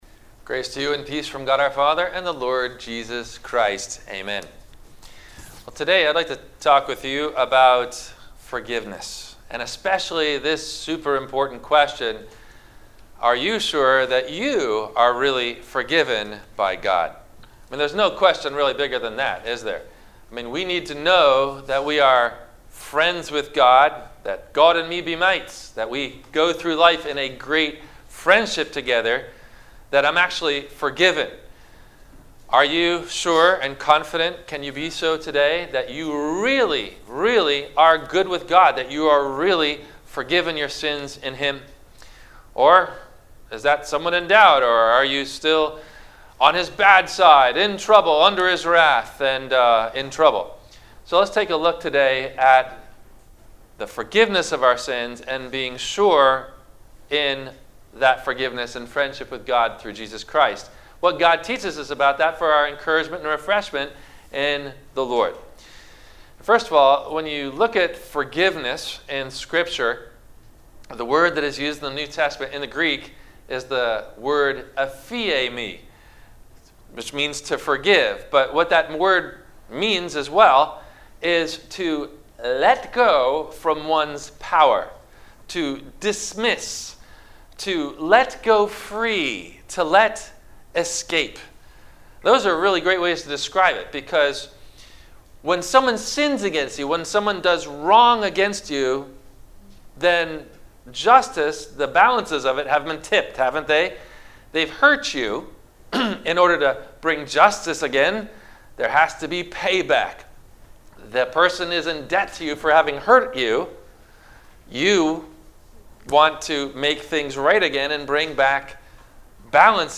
- Sermon - December 20 2020 - Christ Lutheran Cape Canaveral